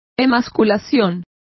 Complete with pronunciation of the translation of emasculation.